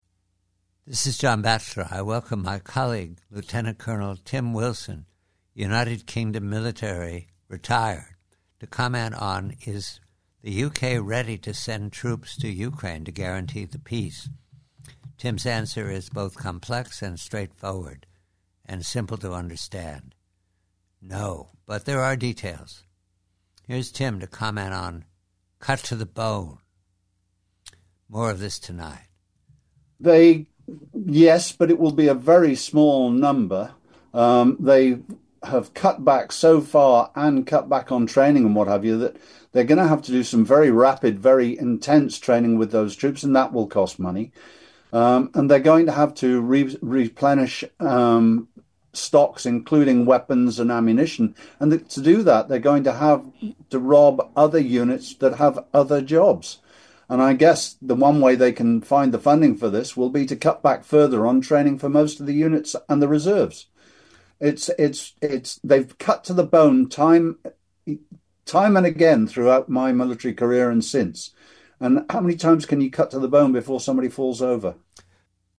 Preview: Conversation